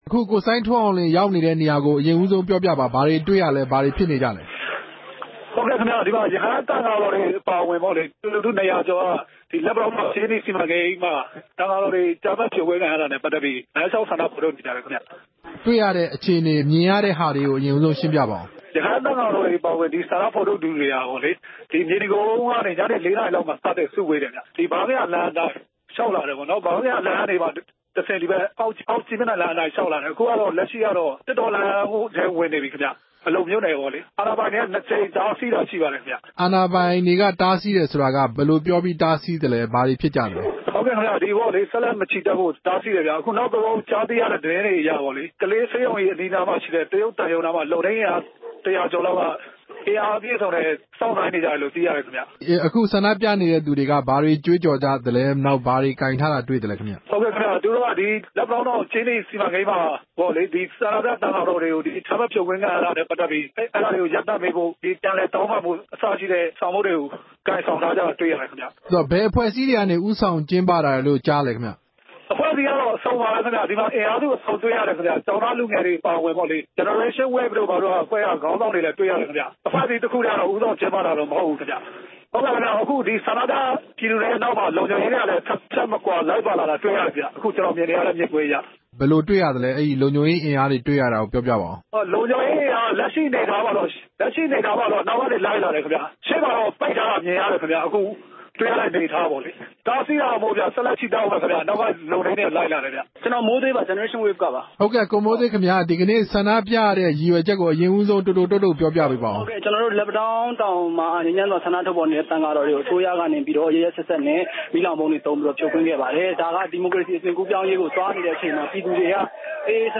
ဆက်သွယ်မေးမြန်းထားတာကိုလည်း တဆက်ထဲနားဆင်နိုင်ပါတယ်။